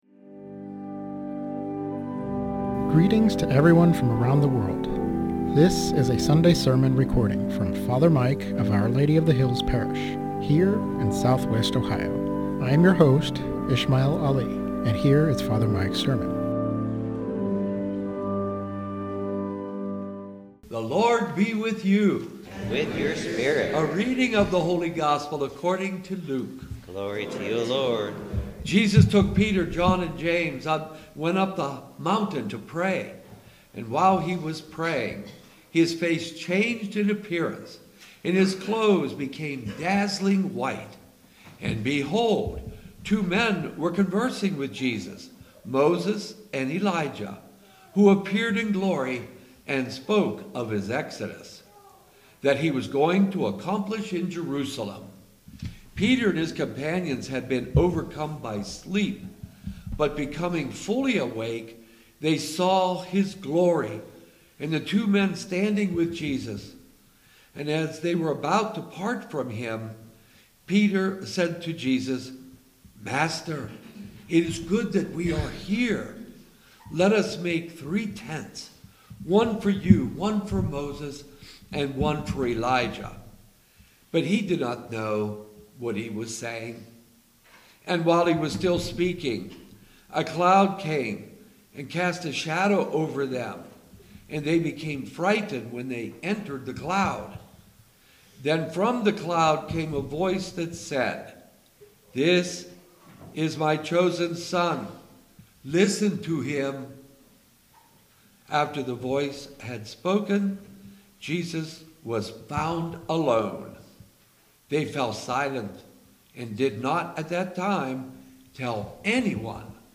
Sermon on Luke 4:1-13 - Our Lady of the Hills - Church